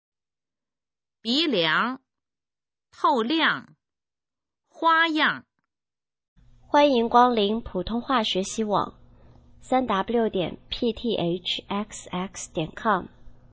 普通话水平测试用必读轻声词语表示范读音第151-200条